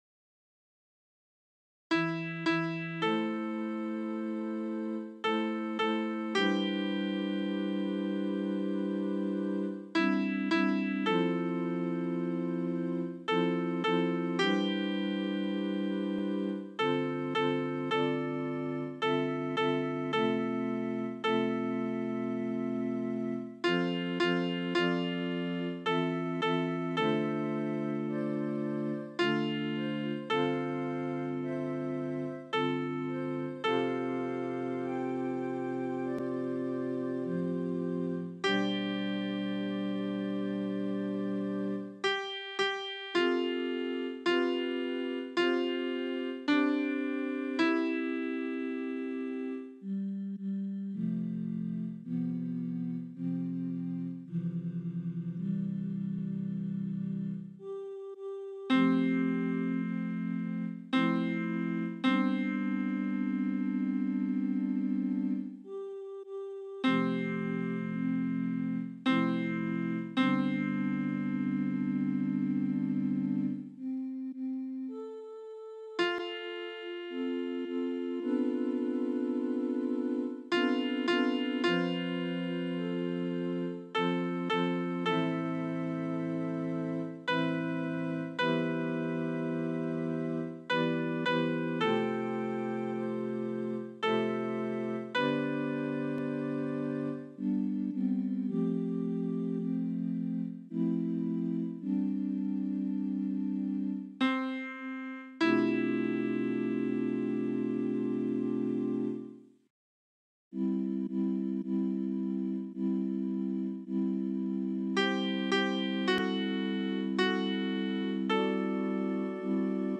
Versions piano
ALTO 1